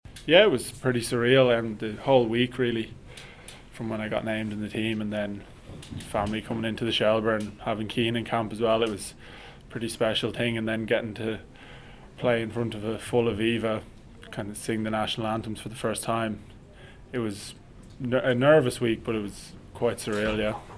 Prendergast speaks ahead of their game against Fiji this afternoon.